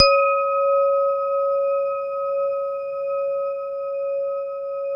WHINE  C#3-L.wav